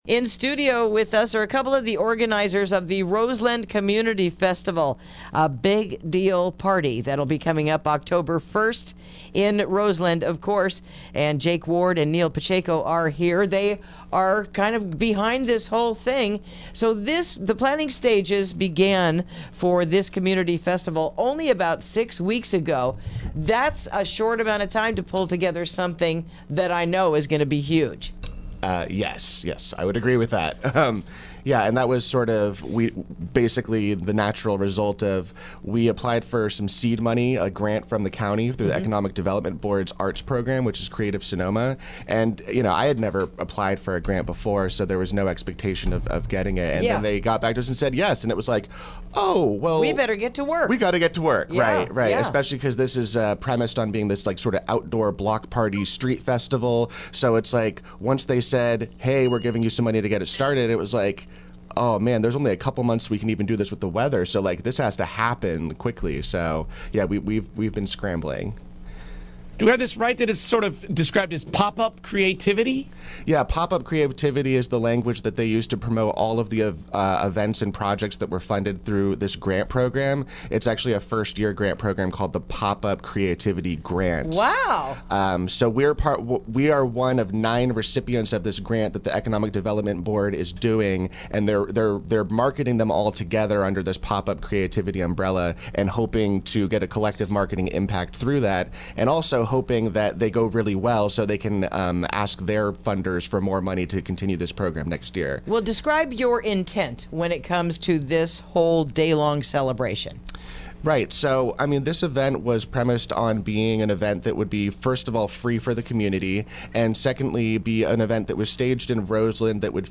Interview: Roseland Community Festival